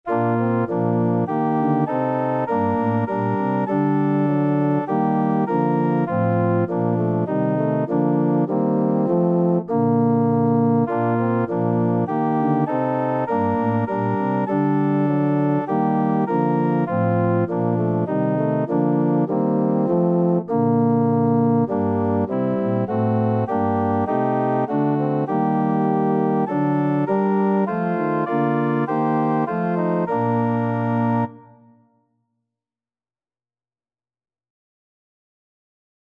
Organ version
4/4 (View more 4/4 Music)
Organ  (View more Intermediate Organ Music)
Christian (View more Christian Organ Music)